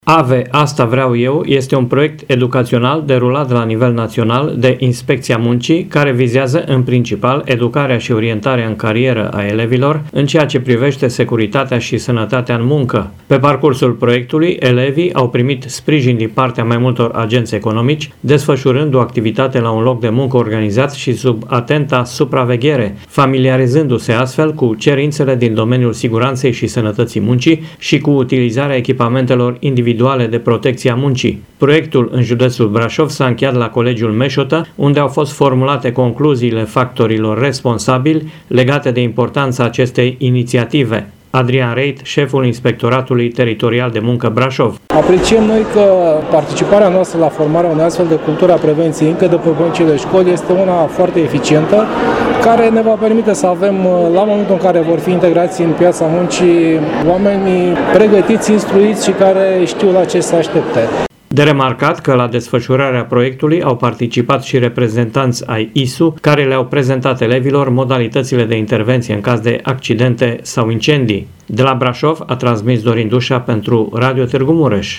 Proiectul în judeţul Braşov s-a încheiat la Colegiul Meşotă, unde au fost formulate concluziile factorilor responsabili, legate de importanţa acestei iniţiative. Adrian Reit, şeful Inspectoratului Teritorial de muncă Braşov